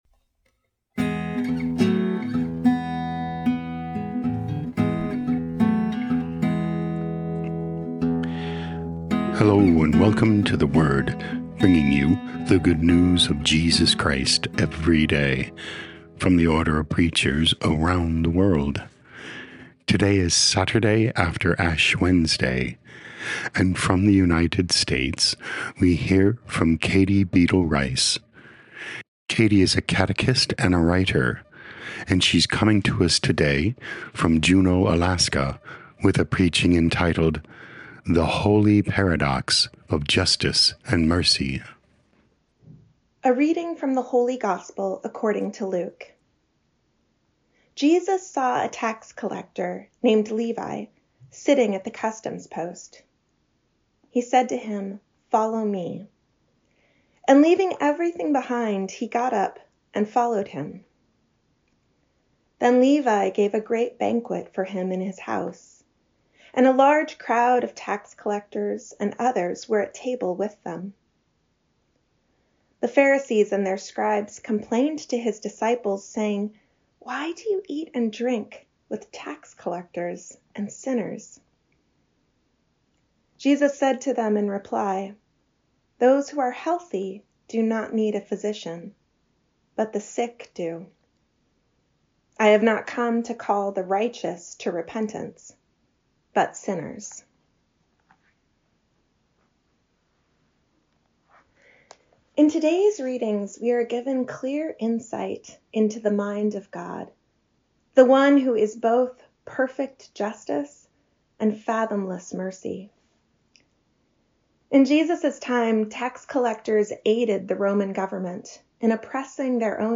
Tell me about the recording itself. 8 Mar 2025 The Holy Paradox of Justice and Mercy Podcast: Play in new window | Download For 8 March 2025, Saturday after Ash Wednesday, based on Luke 5:27-32, Isaiah 58:9b-14, sent in from Juneau, Alaska.